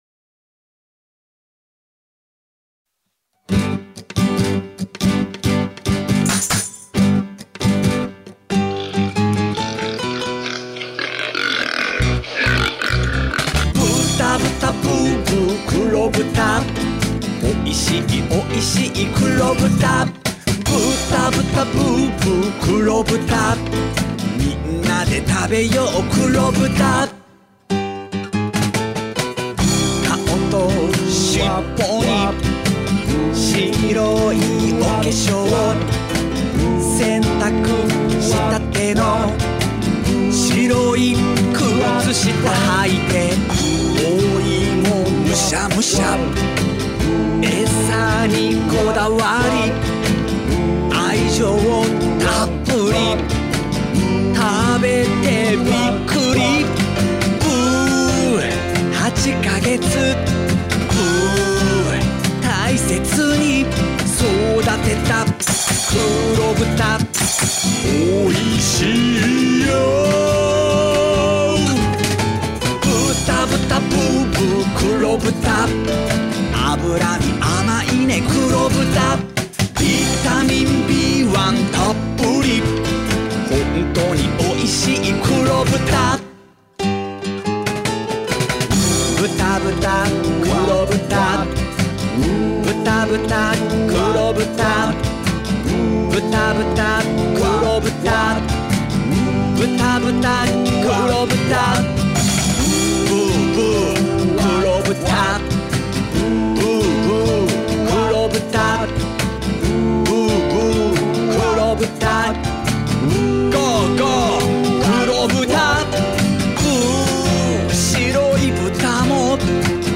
市来農芸高校 応援ソング | 鹿児島県立